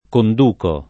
condurre [kond2rre] v.; conduco [